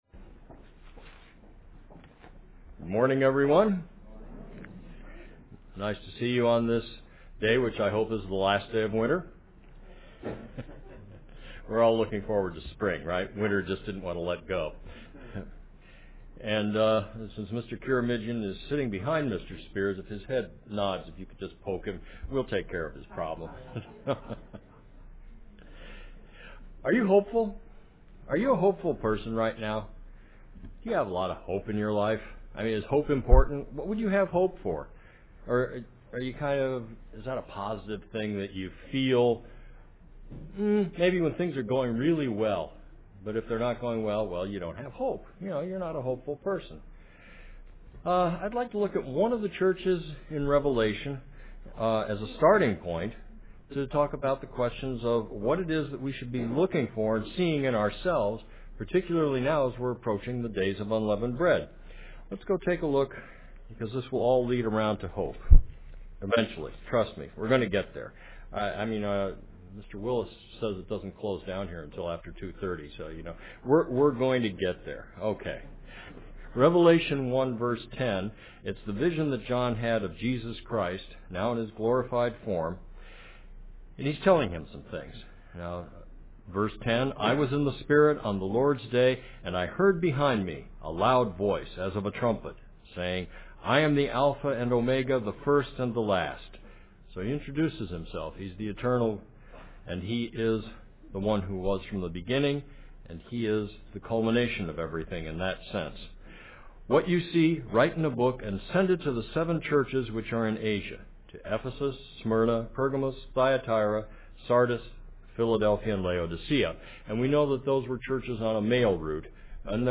UCG Sermon Notes